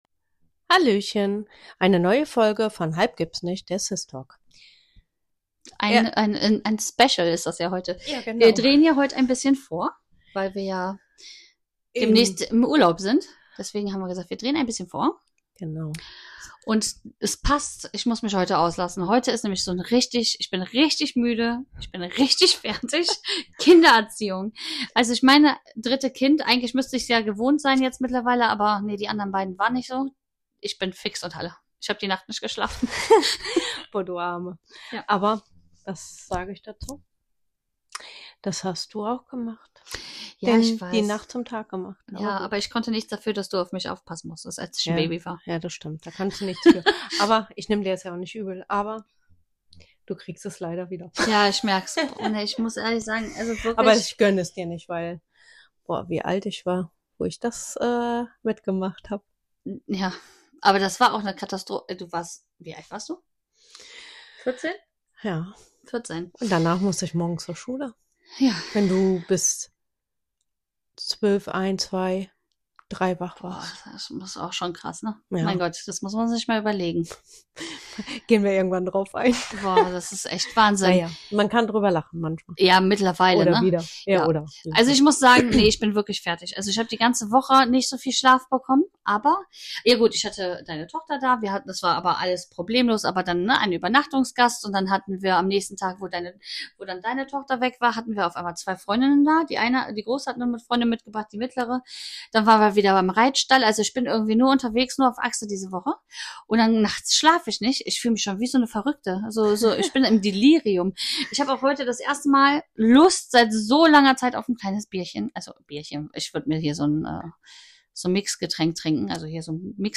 Diese Woche wird’s ernst – aber auch ein bisschen lustig: Die zwei Schwestern sprechen über das Bedürfnis, alles in der Wohnung umzustellen, wenn im Kopf mal wieder Chaos herrscht.